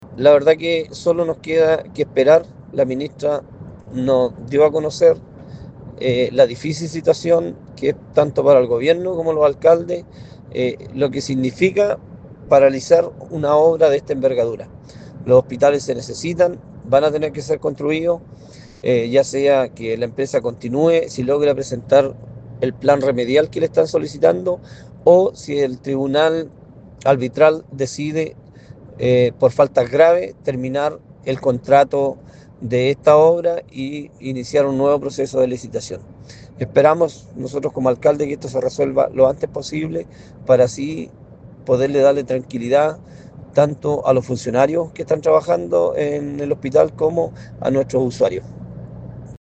A su vez, Pablo Urrutia, alcalde de Quilaco y presidente de ANCORDI, expresó mayor tranquilidad tras la reunión.
Por su parte, Carlos Toloza, alcalde de Nacimiento, señaló que se necesita una mayor “certidumbre para nuestros funcionarios y usuarios.